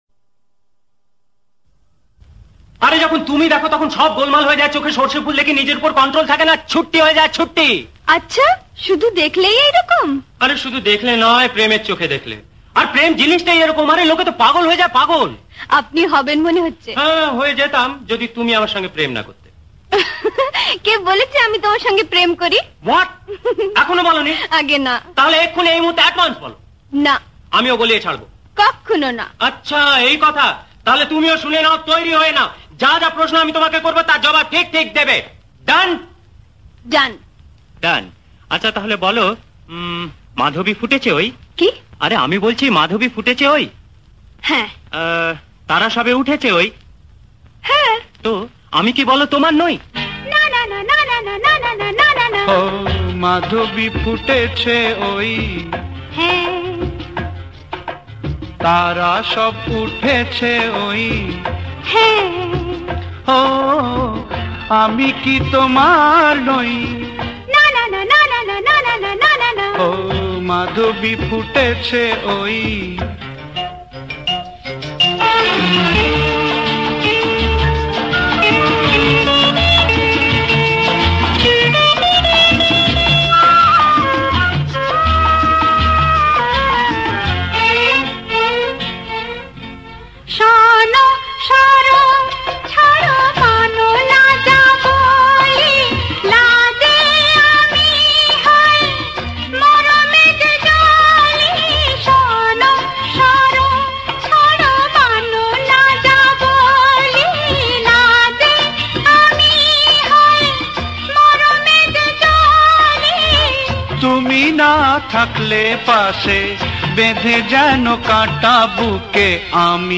classical romantic song